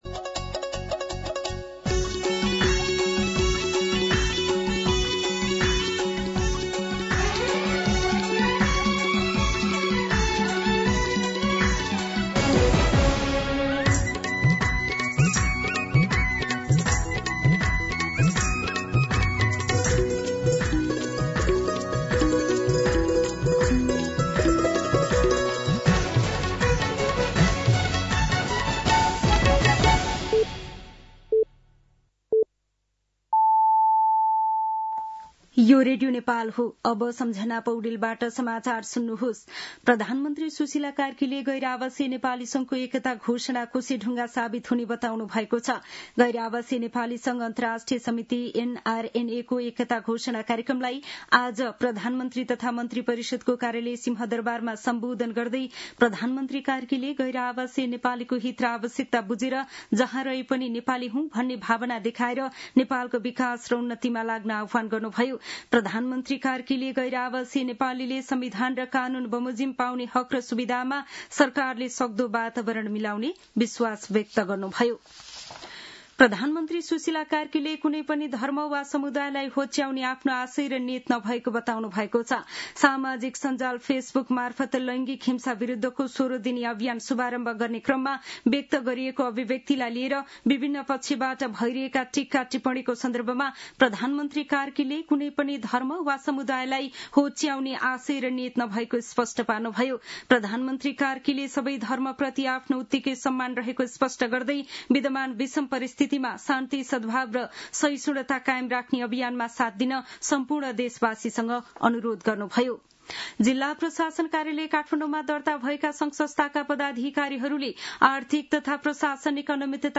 दिउँसो १ बजेको नेपाली समाचार : १४ मंसिर , २०८२